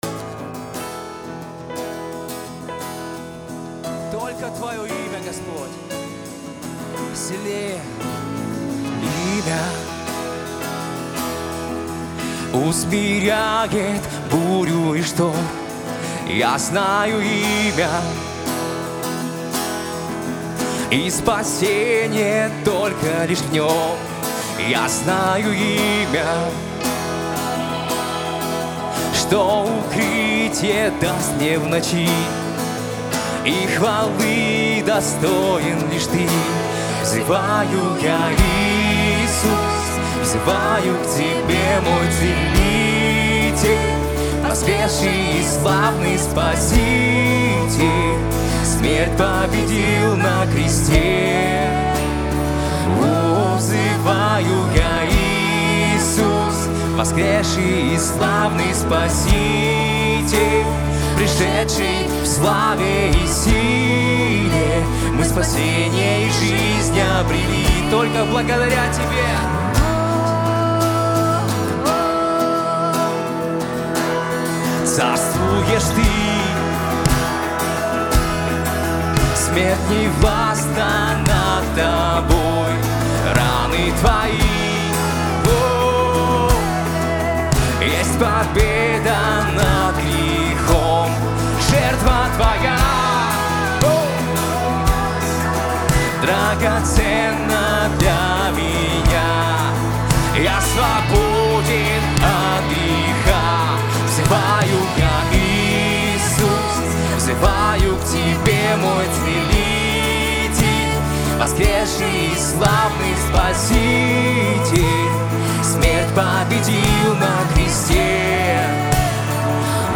94 просмотра 206 прослушиваний 3 скачивания BPM: 86